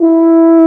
BRS TUBA F0R.wav